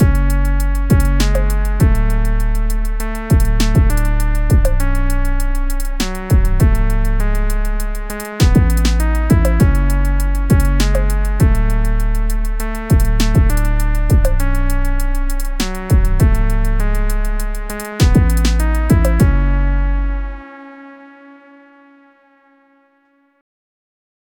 Just used a lil trappy beat I had on my M:S, and made sure to carefully align everything so it was in phase.
Everything is normalized to -1 dbfs True Peak before rendering to MP3 from wav in Reaper, no other processing other than the Big Muff pedal. I didn’t get the hardware plugin part set up so everything is recorded through my audio interface into Reaper from the pedal’s stereo output.
Clean: